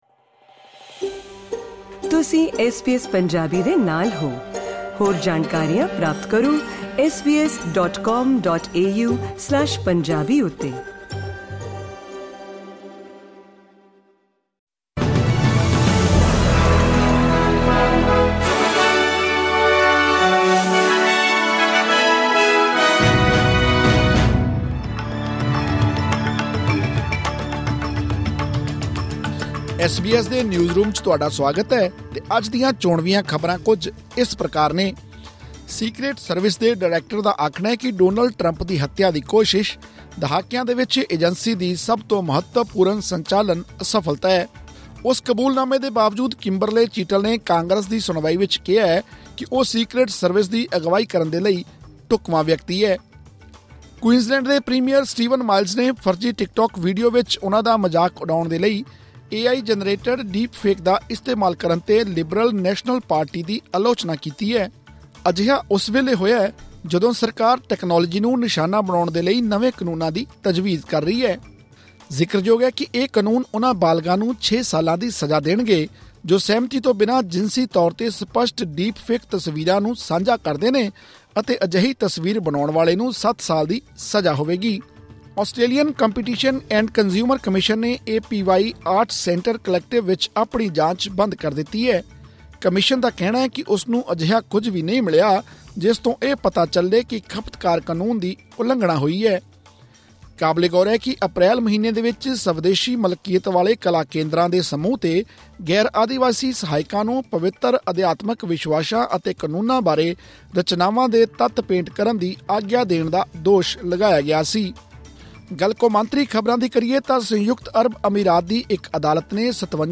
ਐਸ ਬੀ ਐਸ ਪੰਜਾਬੀ ਤੋਂ ਆਸਟ੍ਰੇਲੀਆ ਦੀਆਂ ਮੁੱਖ ਖ਼ਬਰਾਂ: 23 ਜੁਲਾਈ 2024